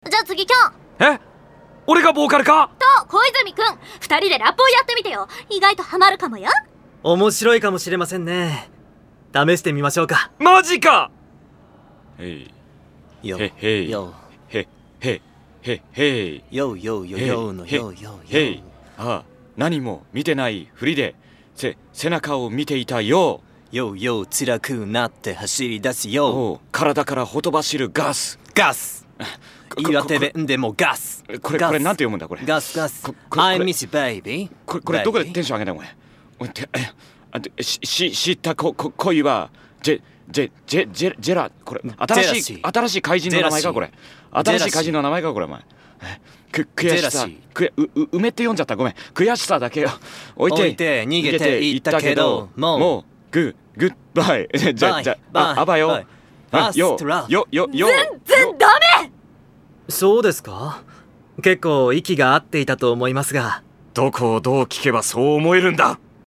cd drama
du rap...